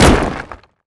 rock_impact_small_hit_02.wav